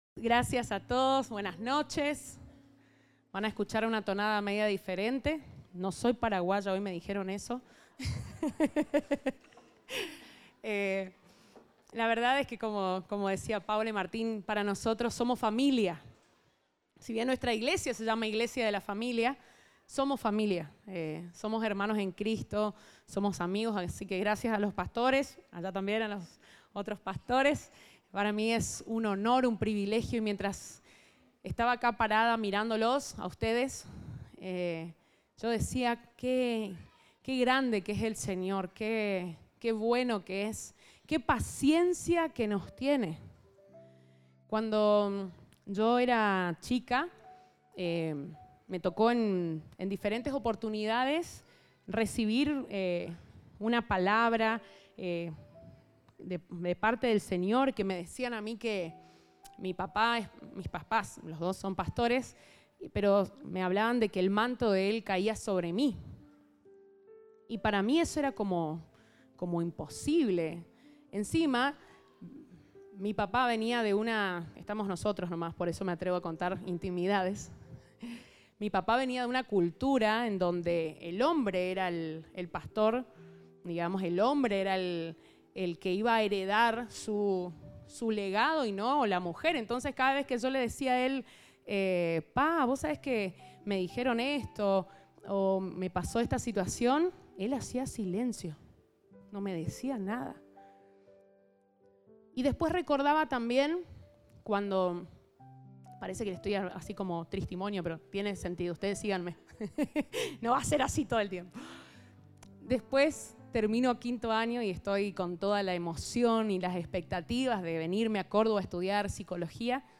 Compartimos el mensaje del Domingo 10 de Setiembre de 2023